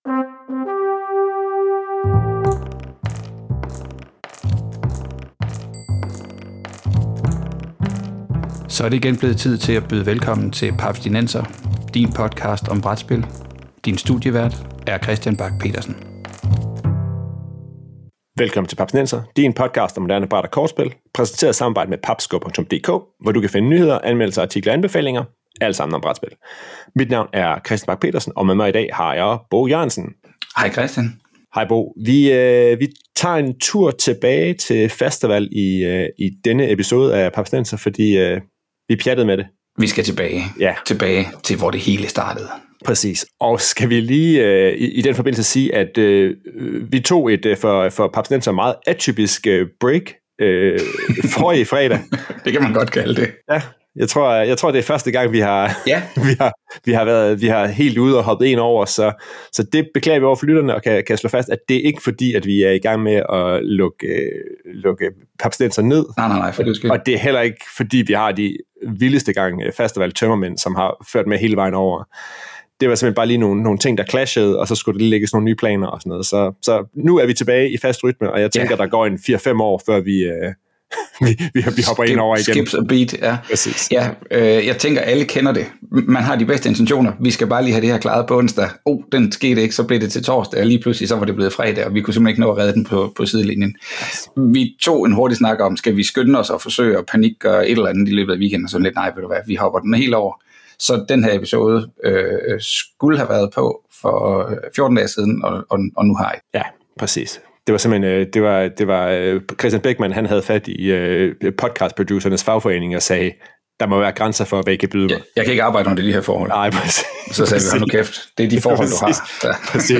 Vi snakker spildesign på Fastaval og hører fra to designere